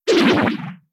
Sound effect of Item Shippo Disappear in Mario Kart 7
MK7_Item_Shippo_Disappear.oga